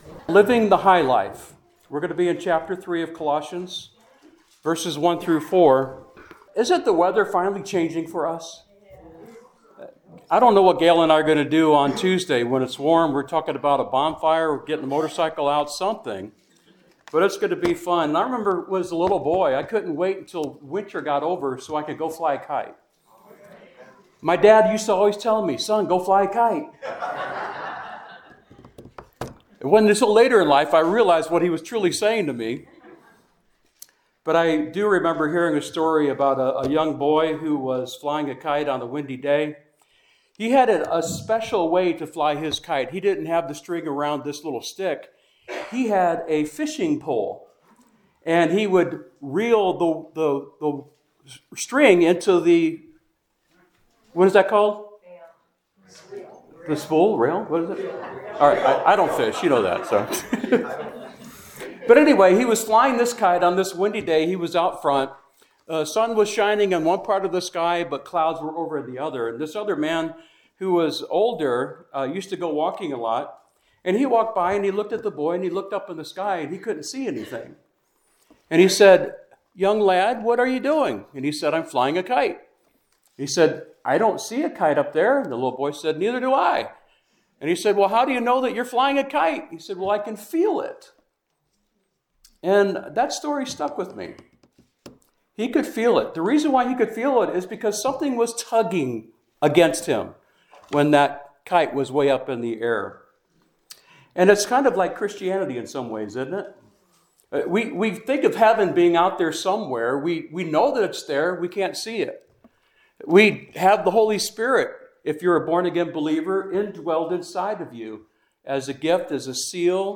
SERMON TITLE: “Living the High Life!”